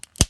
stick.ogg